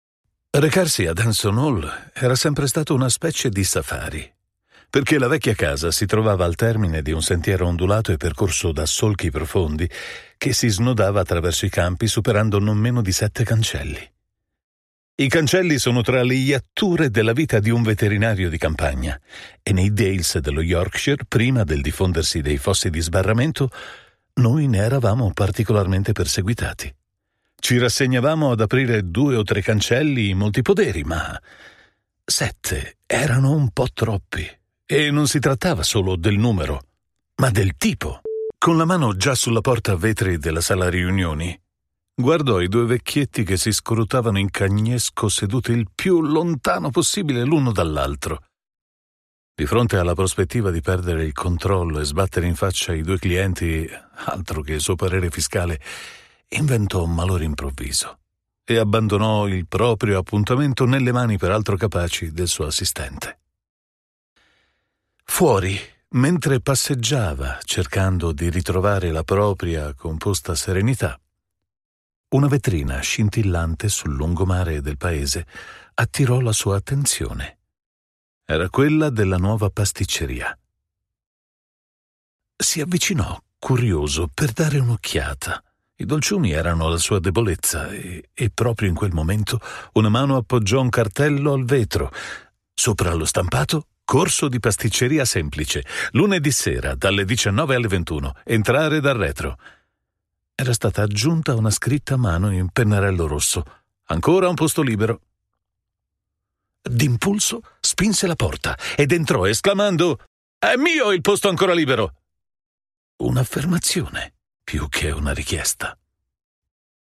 Weltweit bekannte Marken vertrauen ihm und er liefert professionelle Voiceover-Dienste mit Wärme, Klarheit und Schnelligkeit aus seinem hochmodernen Studio.
Erzählung
UAD Apollo X8, Mac Pro, U87Ai, TLM103, TLM67 und mehr.